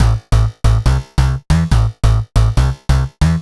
Index of /musicradar/future-rave-samples/140bpm